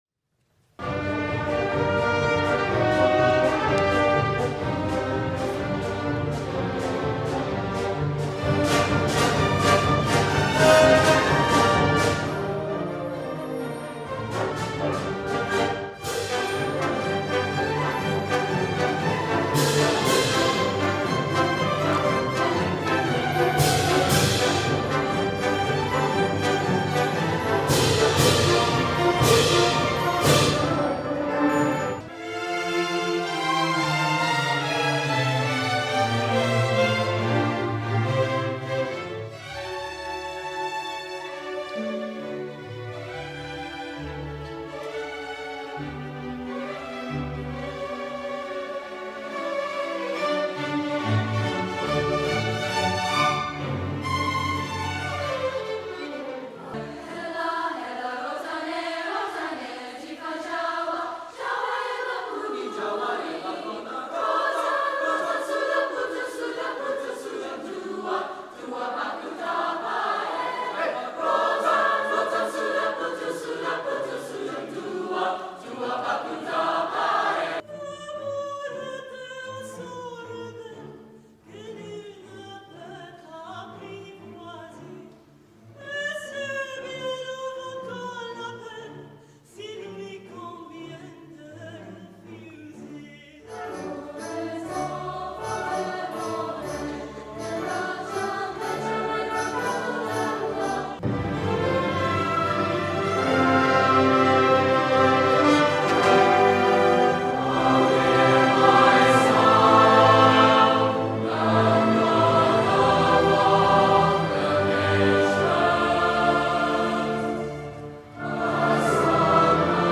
Listen to a sample of student performances from Wesley's Winter Concert 2023 held at Melbourne Recital Centre.
WinterConcert2023MusicExtract.mp3